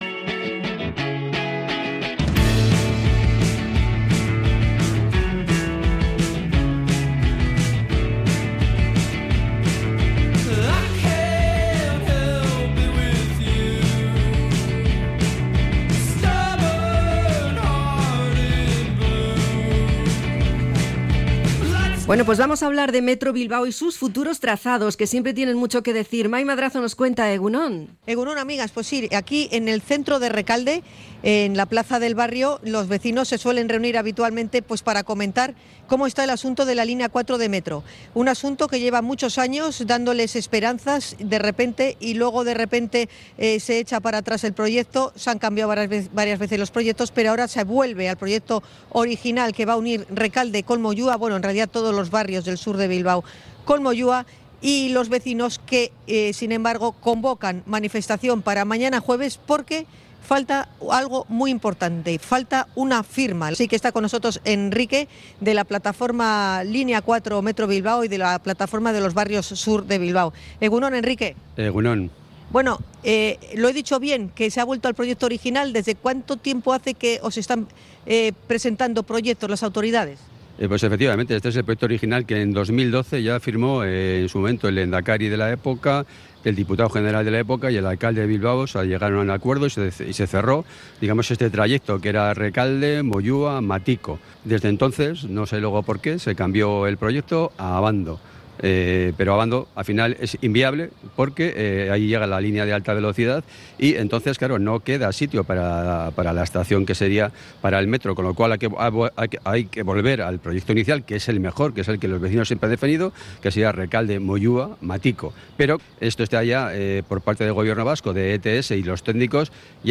Hemos estado en la plaza de Rekalde para conocer los motivos que han llevado a sus vecinos a convocar la manifestación de mañana.